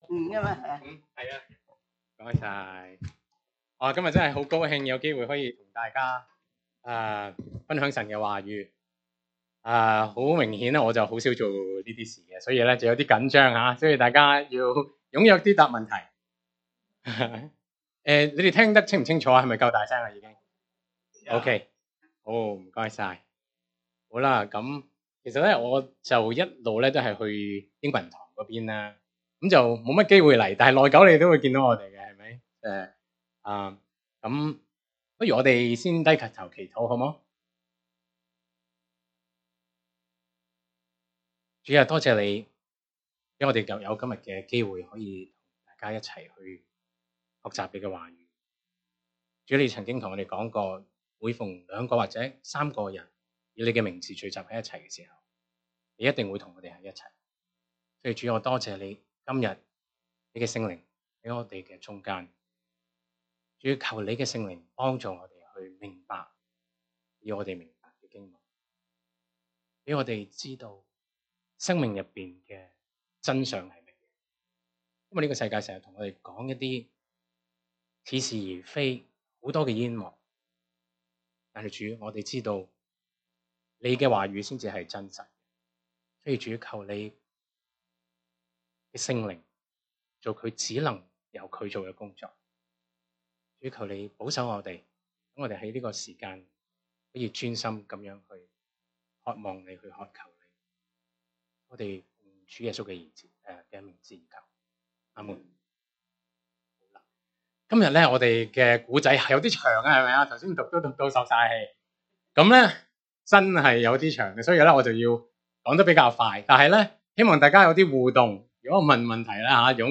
Sermons by CCCI